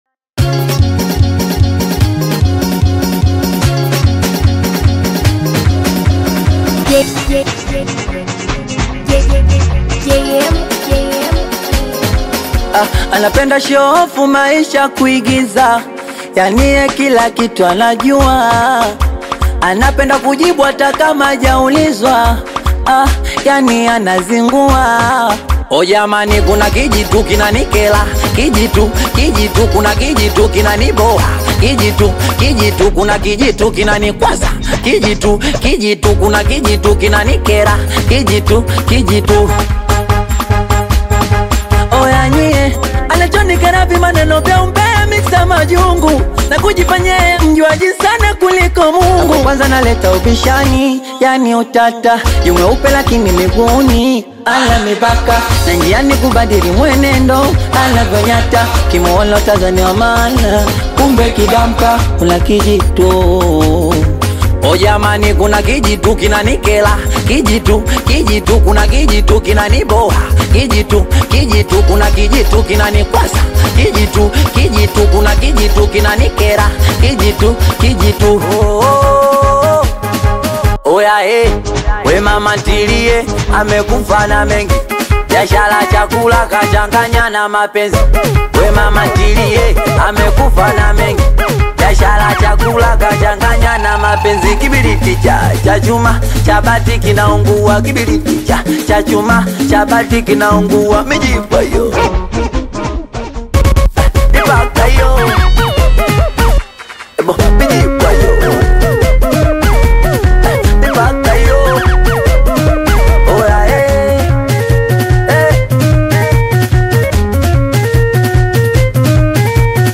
Singeli